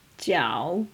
dumpling-jiao3.m4a